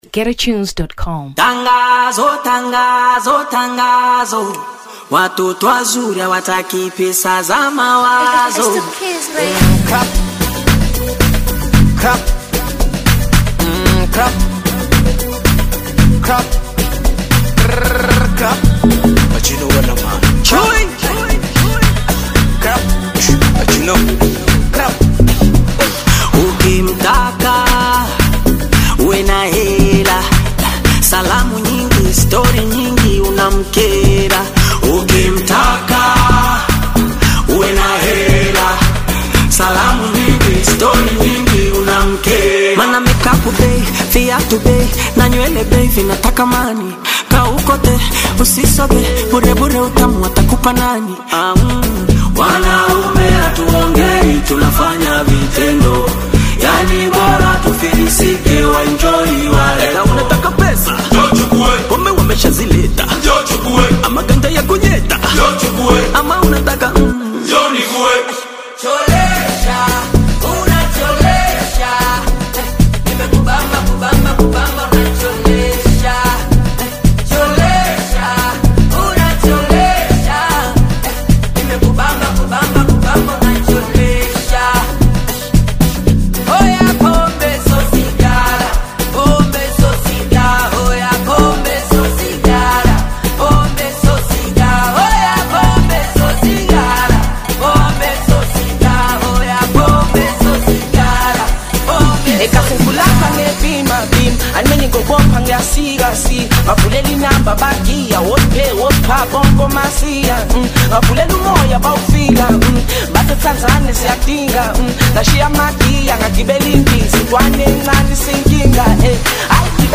Afrobeats 2023 Tanzania